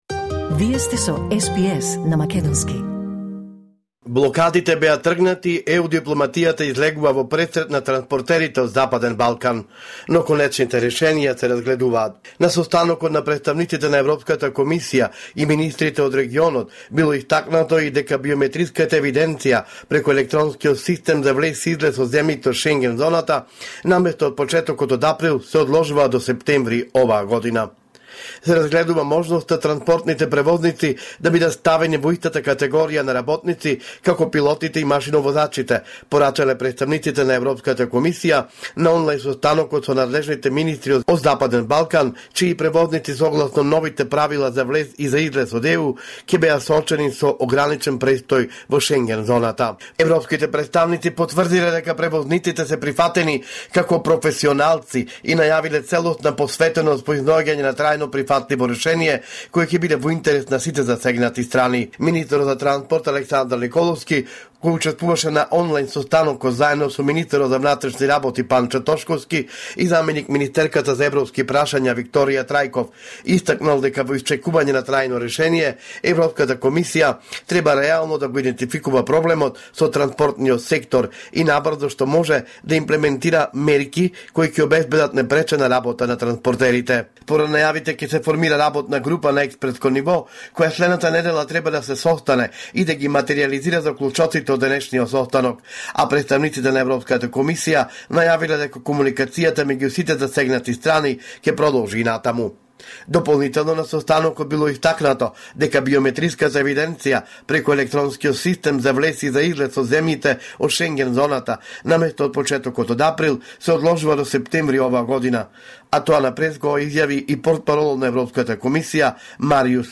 Извештај од Македонија 4 февруари 2026